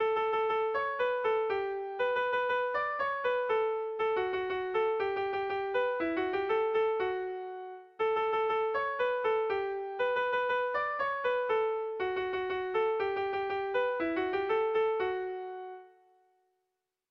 Irrizkoa
ABDABD